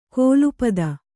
♪ kōlu pada